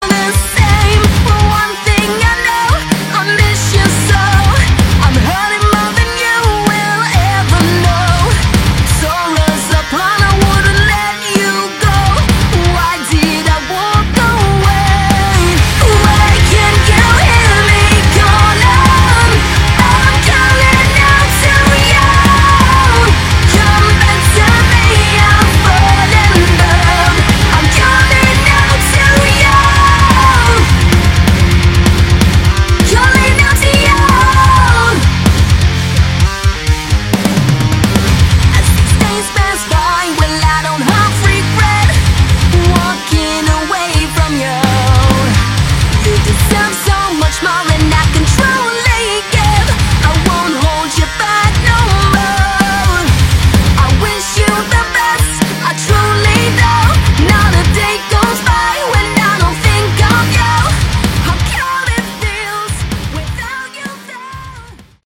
Category: Melodic Metal
lead vocals, guitars
bass, backing vocals
drums